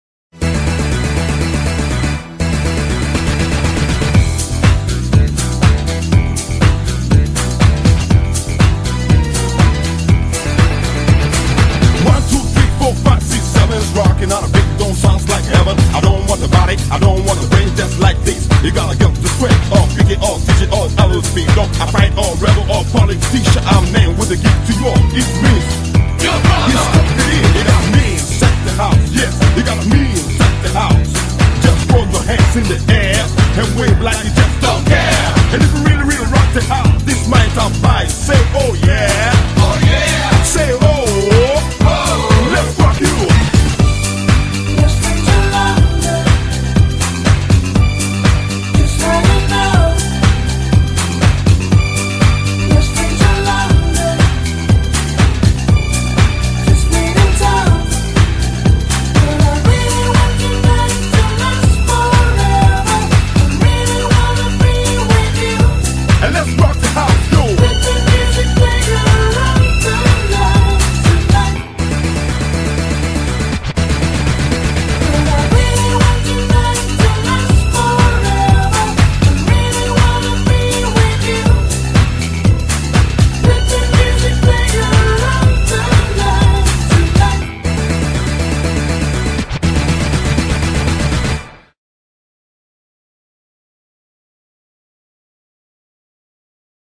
BPM121--1
Audio QualityPerfect (High Quality)